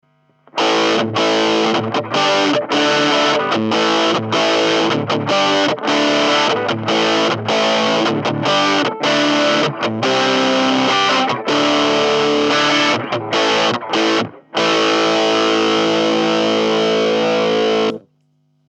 Mooer CruncherПедаль хай-гейн дисторшна.
4. Cruncher Full Gain 442,49 Кб
cruncher_full_gain.mp3